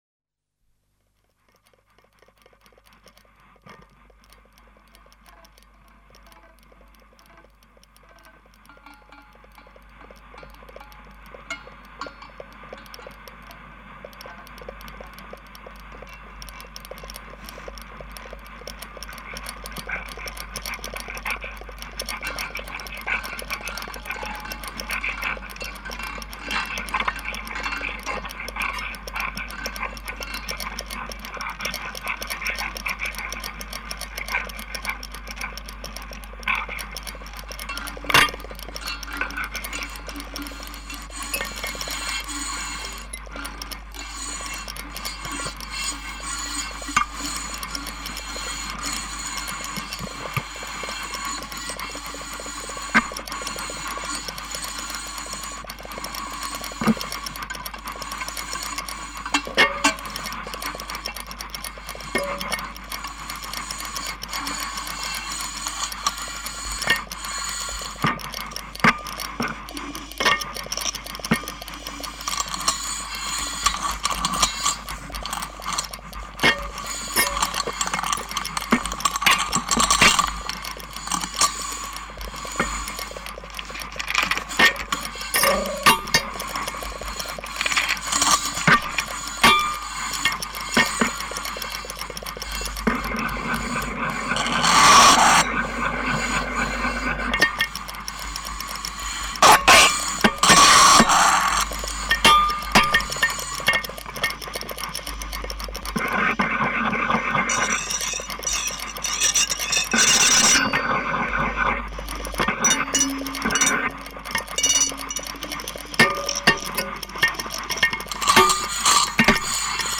improvised and contemporary music
An archive recording from 1969
in a previously unpublished improvised duo.